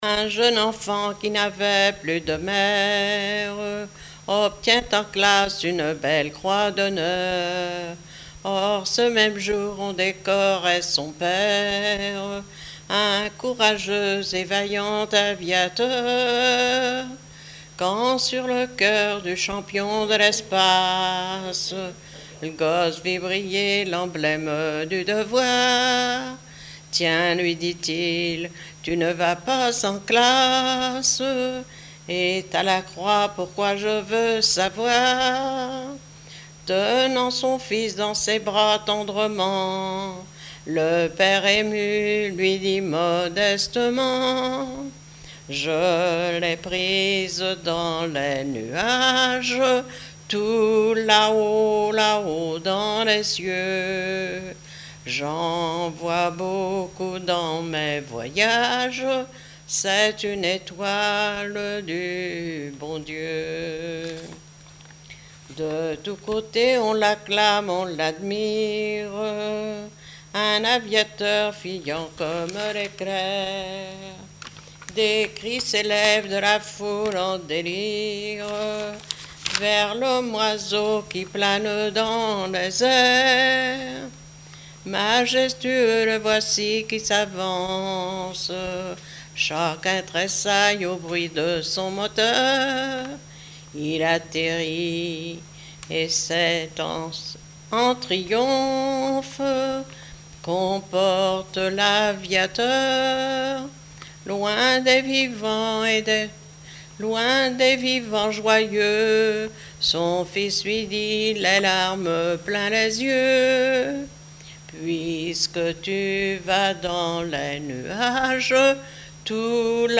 chansons locales et chansons populiares
Pièce musicale inédite